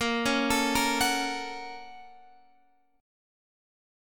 A#mM7#5 chord